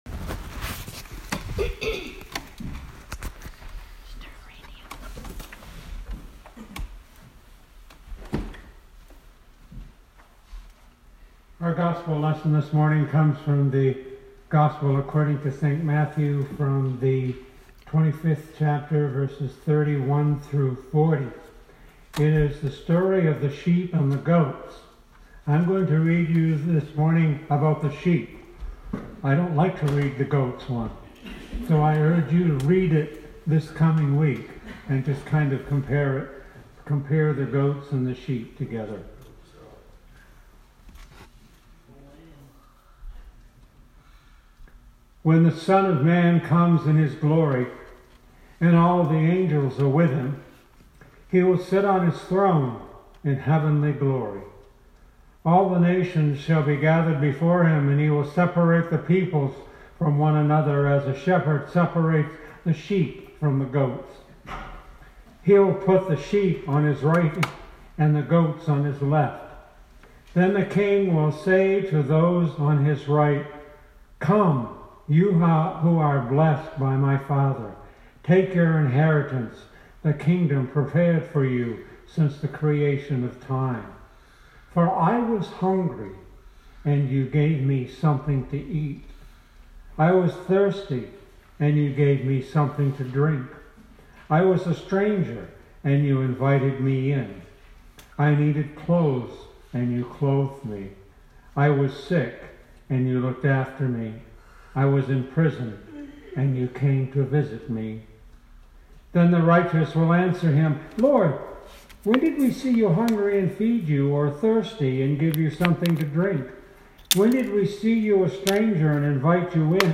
Sermon 2019-10-20